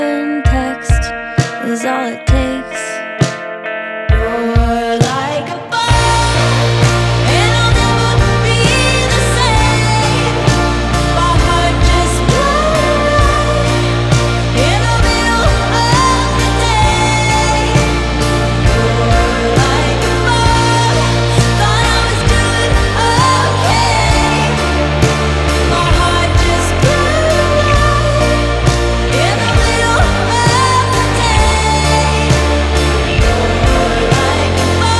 Жанр: Поп / Альтернатива